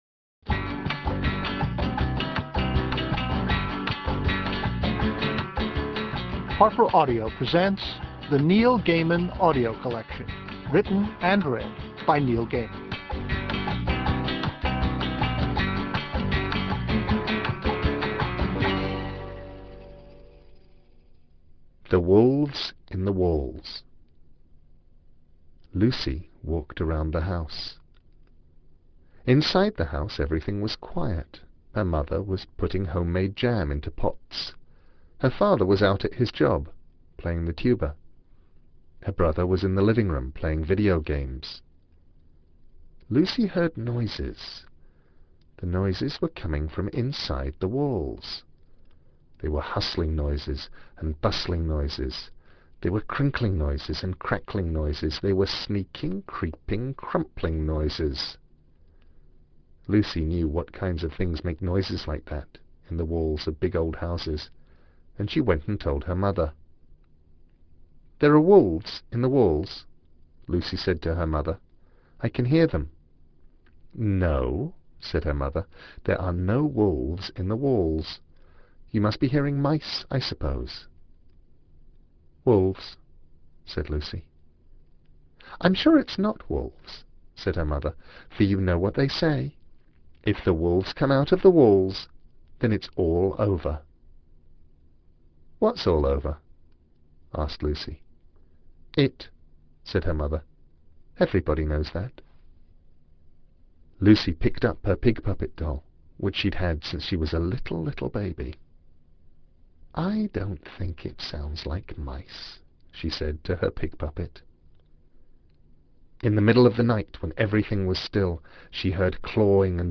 Four of beloved author Neil Gaiman's delightfully scary, strange, and hilarious children's tales read by the author, now available unabridged.